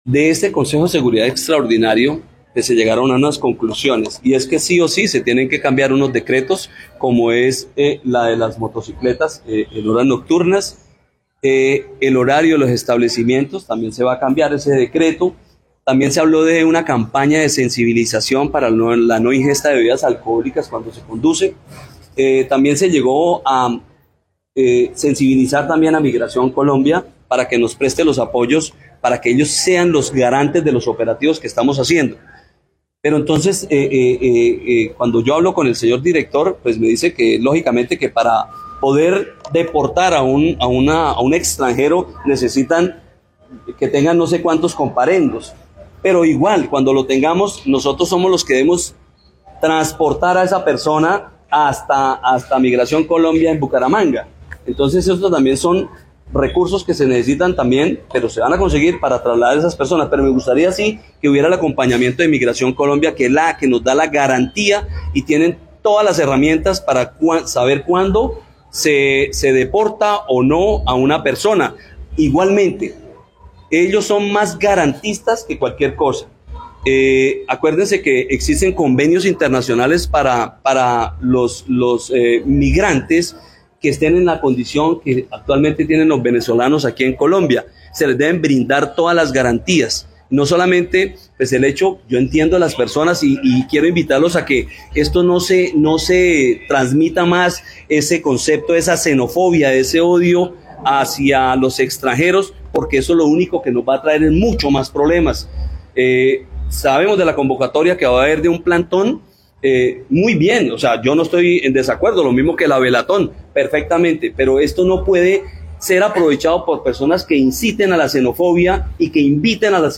Orlando Quintero, secretario de gobierno de San Gil